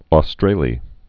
Triangulum Aus·tra·le
(ô-strālē)